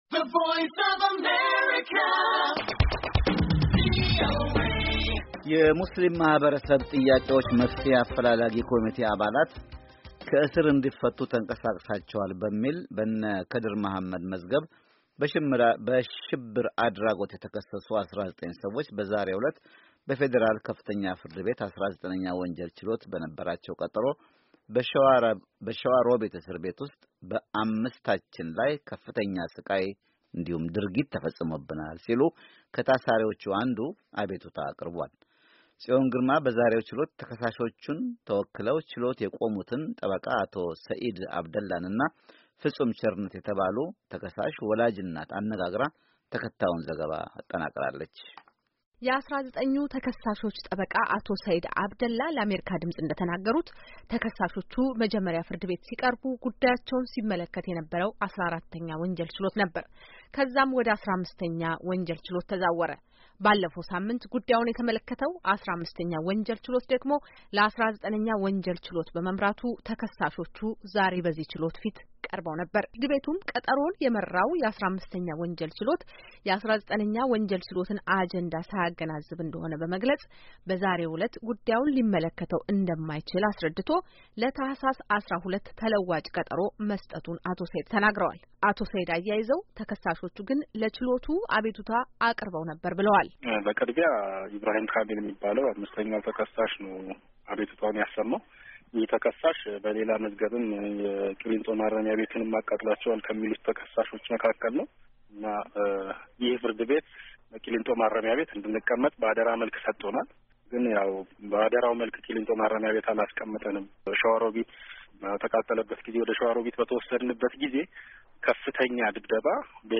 ተከታዩን ዘገባ አጠናቅራለች።